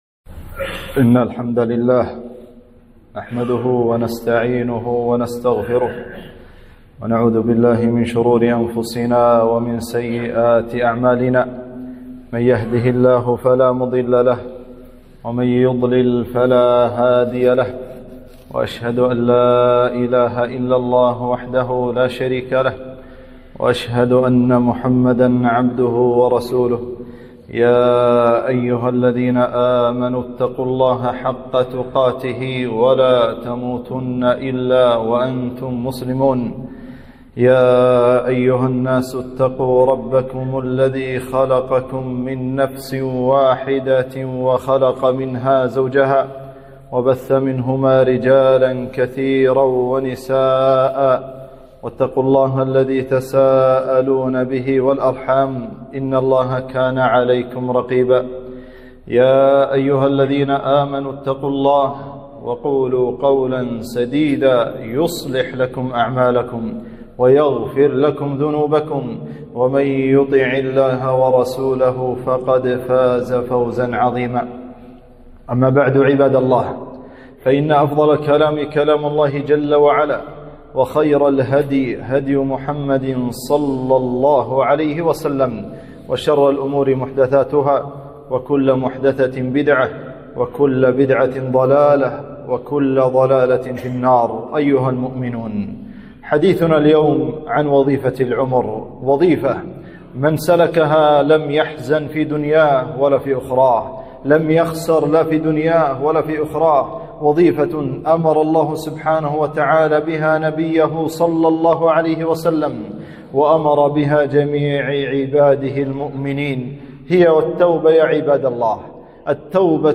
خطبة - التوبة وظيفة العمر